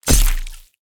blade_hit_07.wav